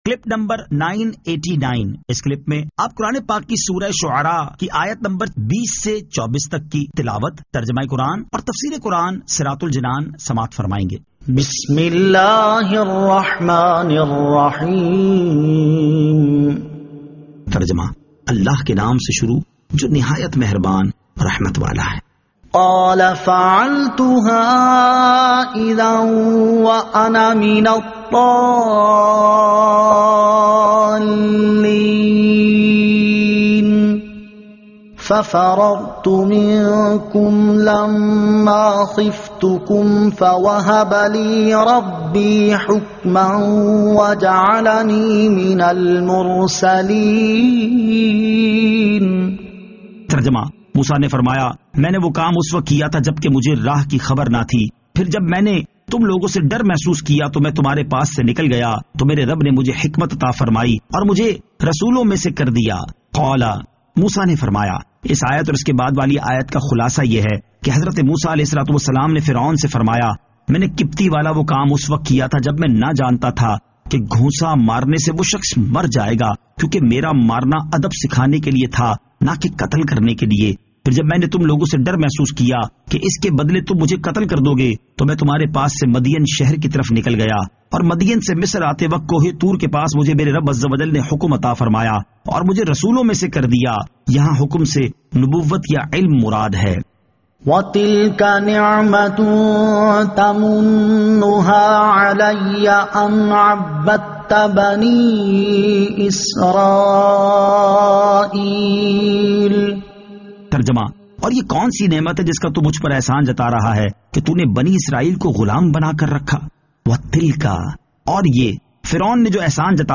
Surah Ash-Shu'ara 20 To 24 Tilawat , Tarjama , Tafseer